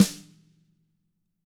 Index of /90_sSampleCDs/ILIO - Double Platinum Drums 1/CD4/Partition B/WFL SNRD